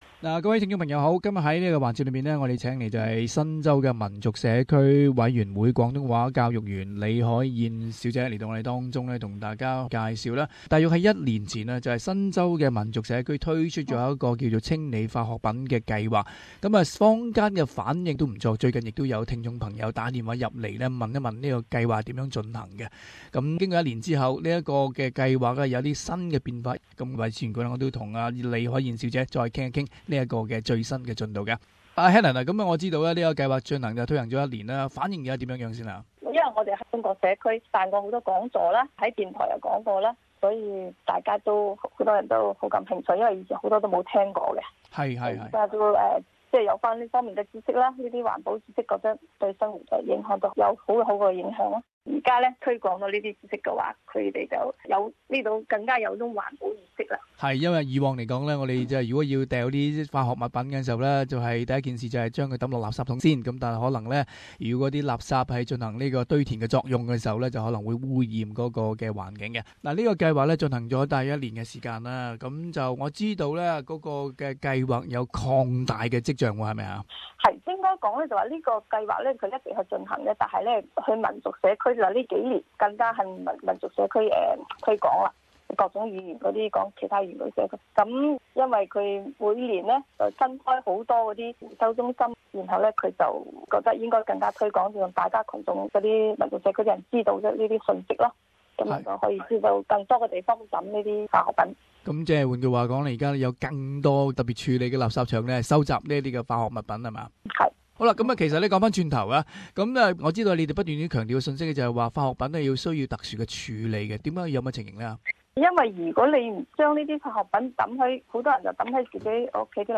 【社团专访】新州民族社区委员会推出清理化学品计划取得一定成果